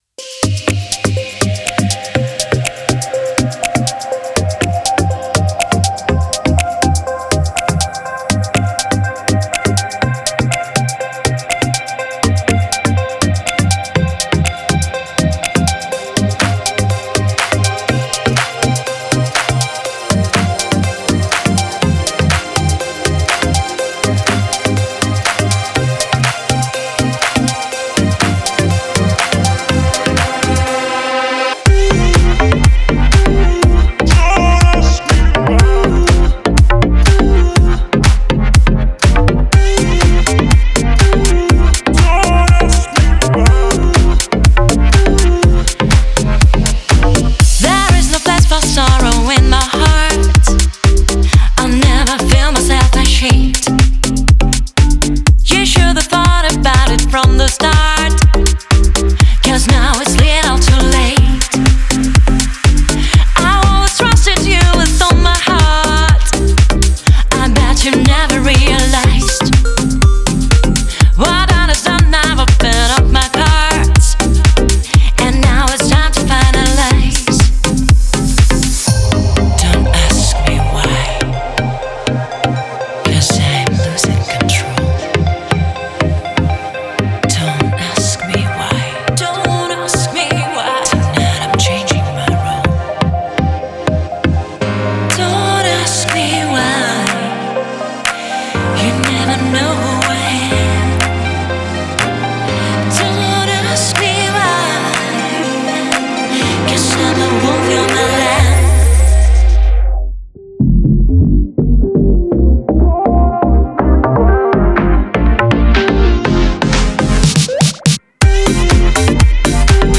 это энергичная трек в жанре электронной поп-музыки